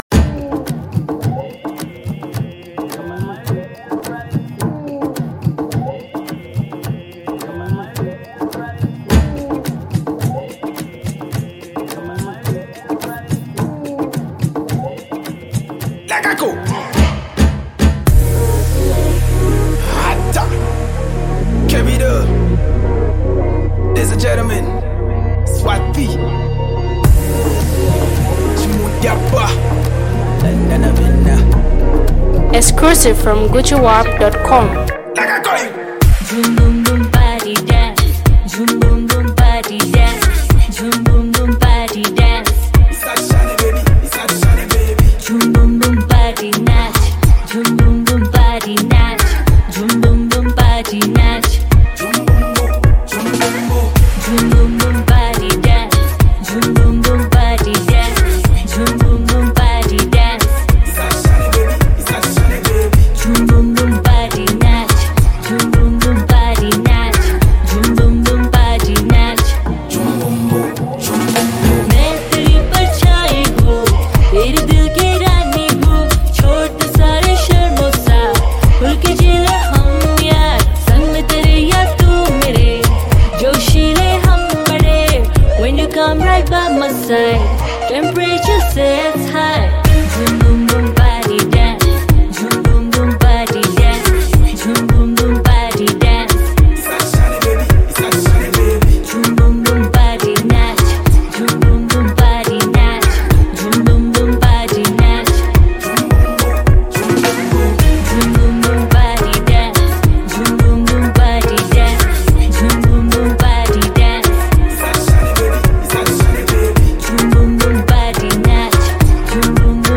rapper and artist
Vocalist